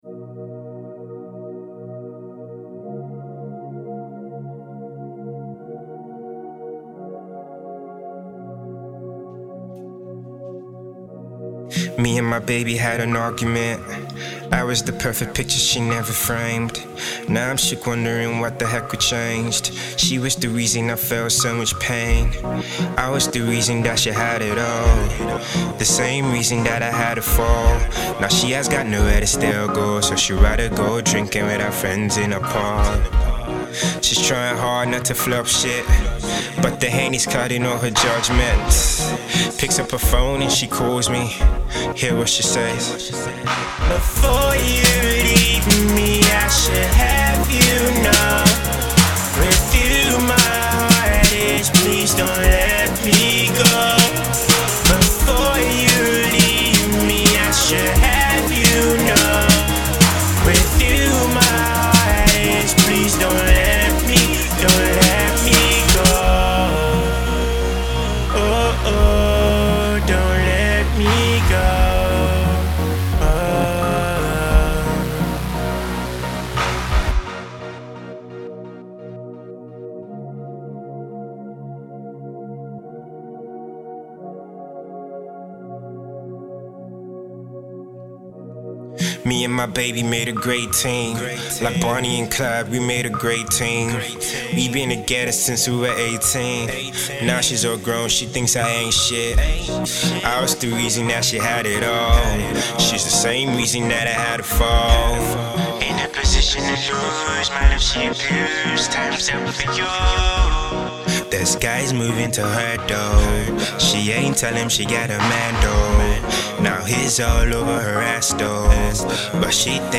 croons sombre melodies